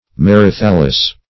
Search Result for " merithallus" : The Collaborative International Dictionary of English v.0.48: Merithal \Mer"i*thal\, Merithallus \Mer`i*thal"lus\, n. [NL. merithallus, fr. Gr.